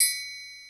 BIG PERC (25).wav